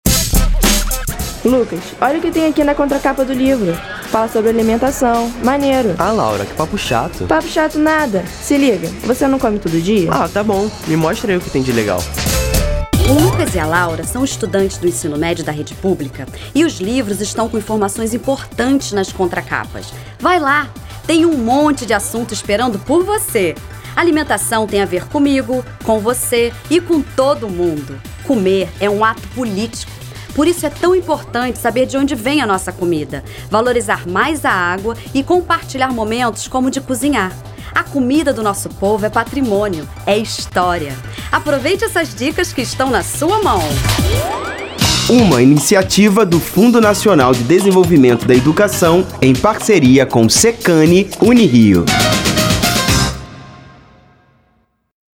Spot de rádio - Capas do Ensino Médio